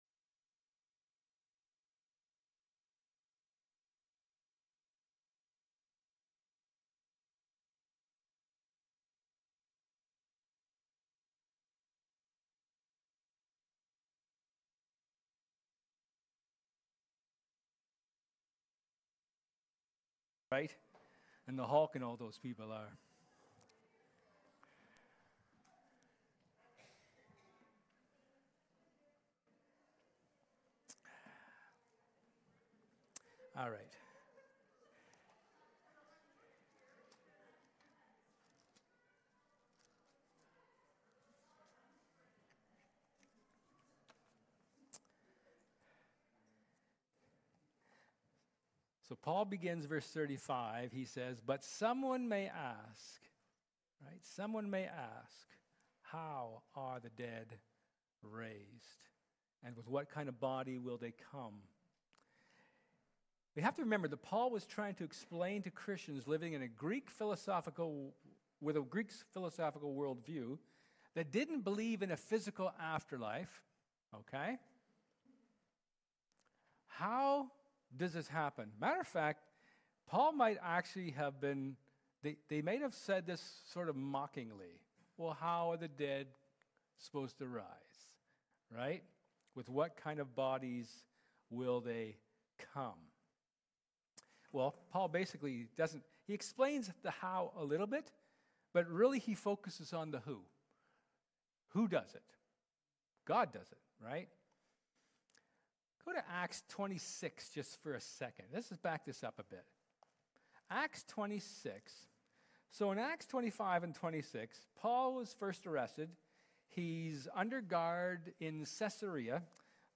1 Corinthians 15:35-49 Service Type: Sermon Paul explains as best he can how our resurrection will happen.